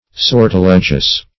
Sortilegious \Sor`ti*le"gious\